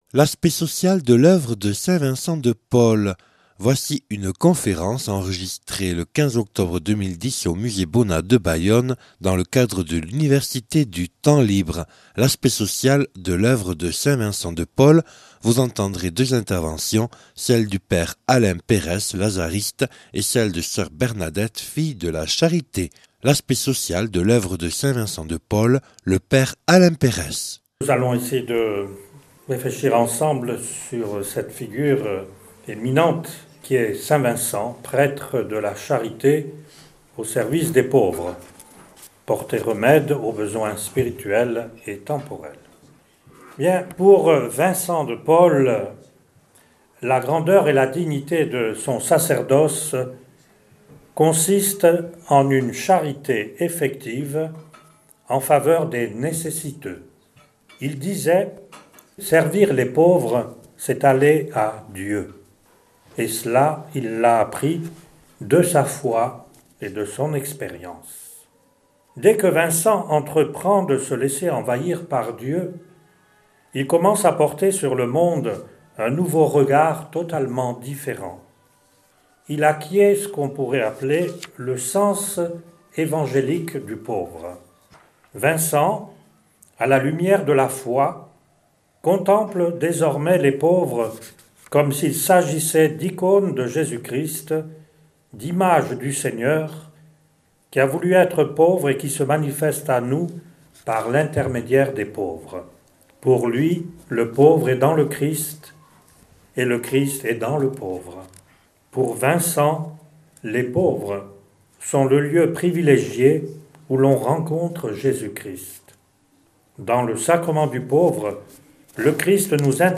(Enregistré le 15/10/2010 au Musée Bonnat de Bayonne lors de l’Université du Temps Libre de Bayonne)